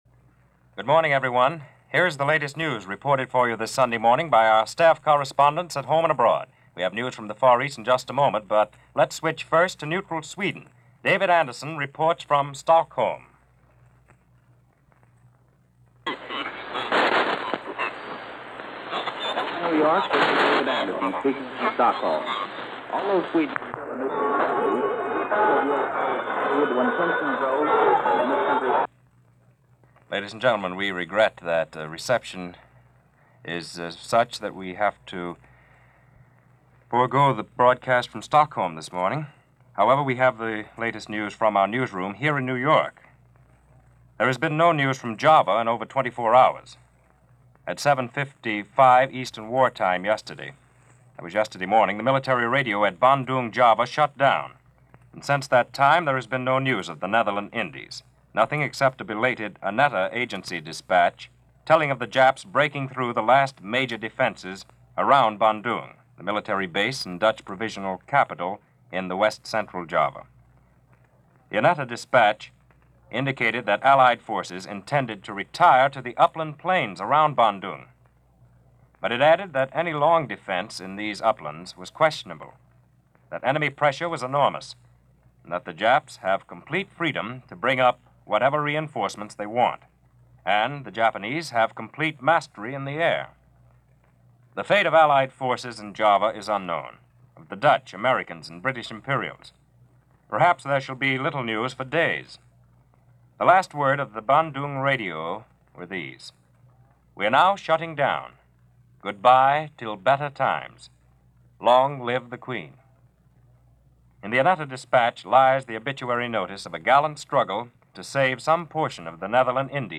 – March 8, 1942 – News Of The World – Gordon Skene Sound Collection –